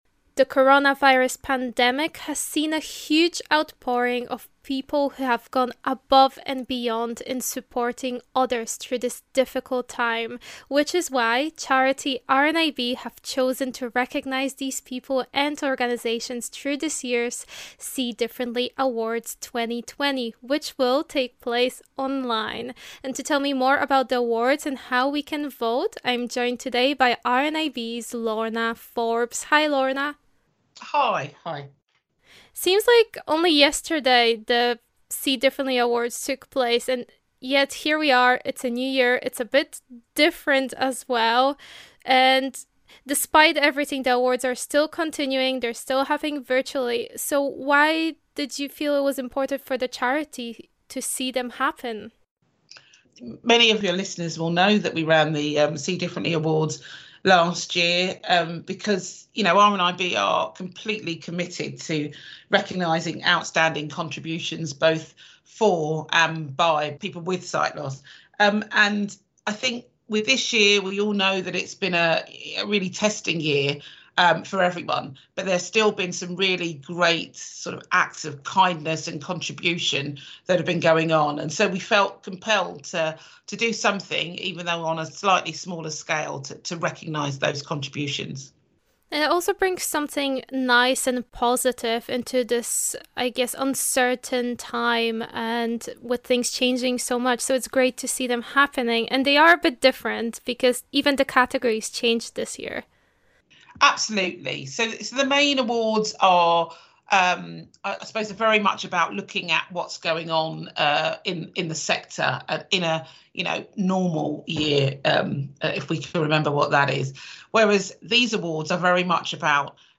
spoke with RNIB Connect Radio about this year's awards and their importance during a time of uncertainty.